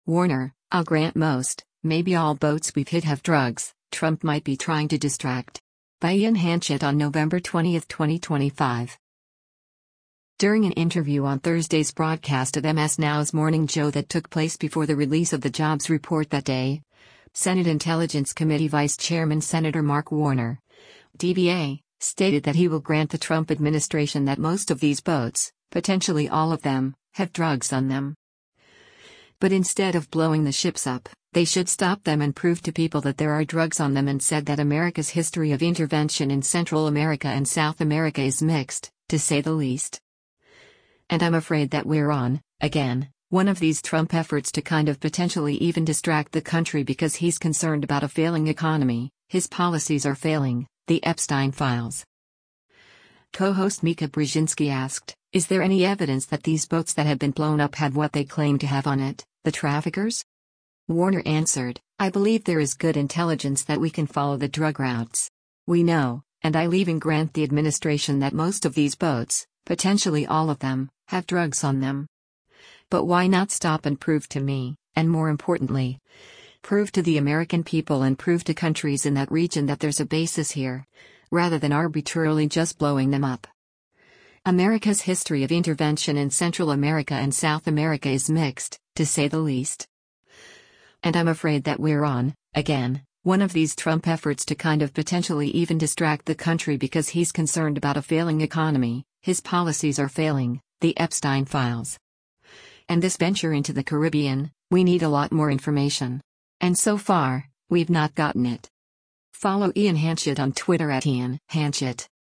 During an interview on Thursday’s broadcast of MS NOW’s “Morning Joe” that took place before the release of the jobs report that day, Senate Intelligence Committee Vice Chairman Sen. Mark Warner (D-VA) stated that he will grant the Trump administration “that most of these boats, potentially all of them, have drugs on them.”